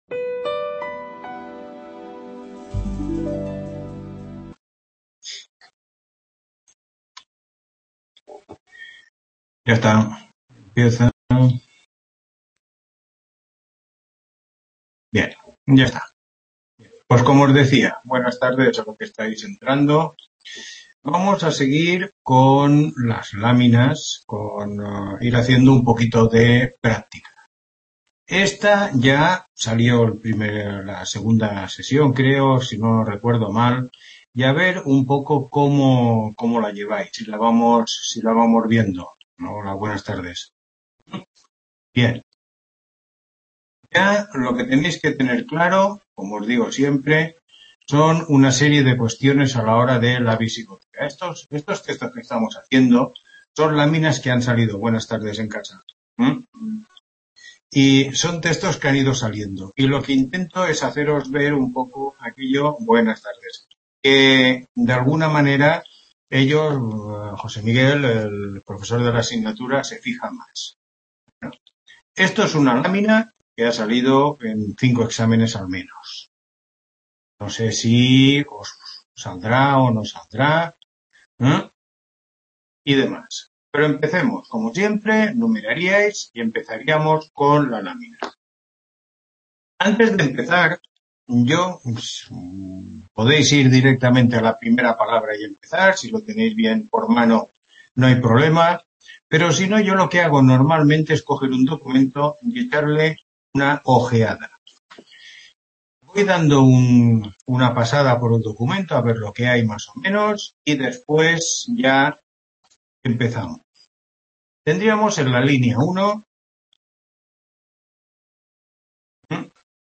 Tutoría 7